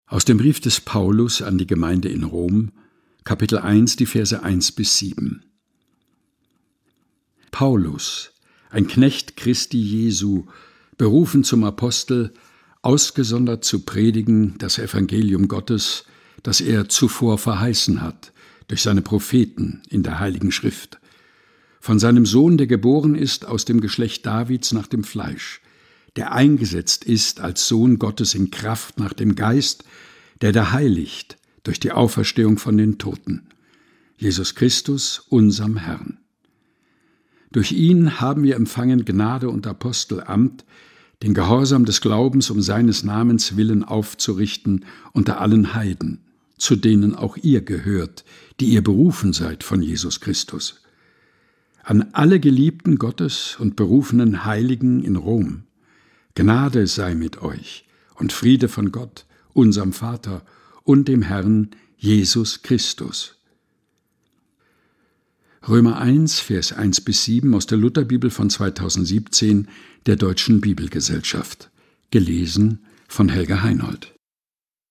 Predigttext zum Christfest II des Jahres 2024.